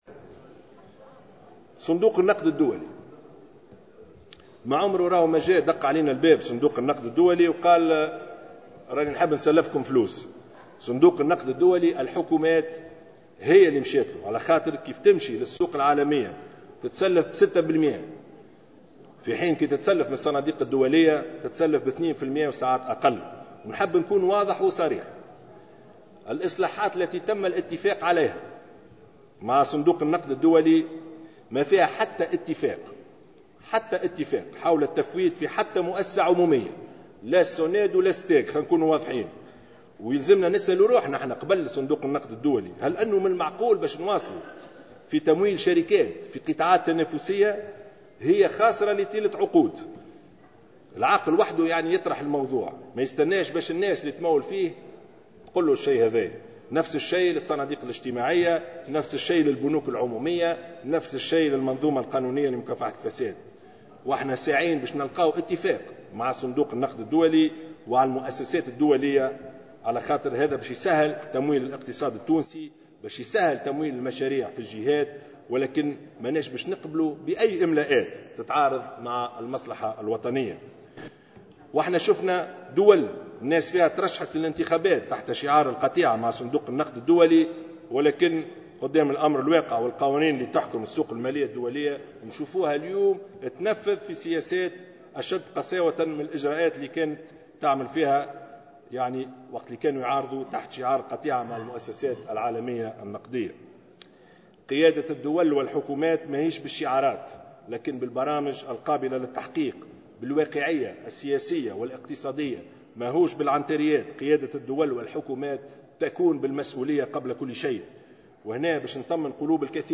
نفى رئيس الحكومة يوسف الشاهد خلال كلمة ألقاها في افتتاح الجلسة العامة بمجلس نواب الشعب اليوم، ما تم تداوله بخصوص الاتفاق مع صندوق النقد الدولي للتفويت في مؤسسات عمومية على غرار الشركة التونسية للكهرباء والغاز والشركة الوطنية لاستغلال وتوزيع المياه.